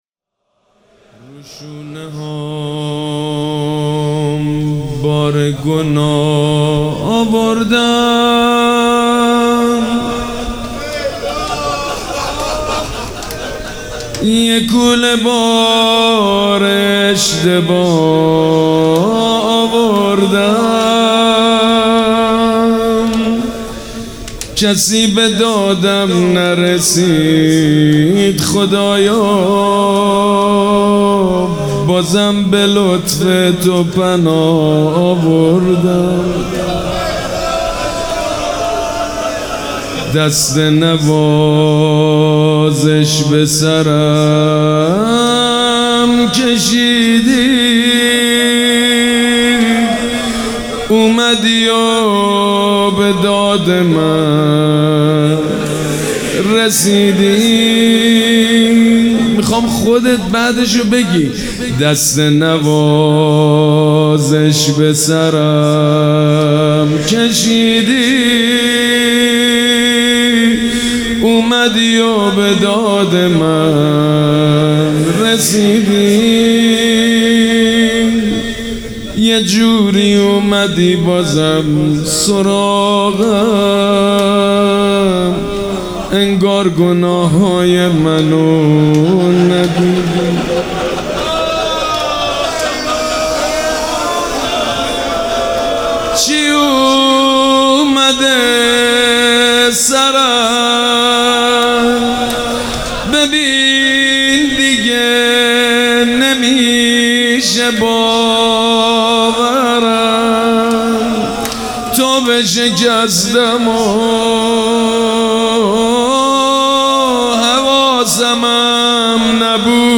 به مناسبت فرارسیدن لیالی قدر و ایام شهادت حضرت علی(ع)، مناجات‌خوانی شنیدنی سید مجید بنی‌فاطمه را می‌شنوید.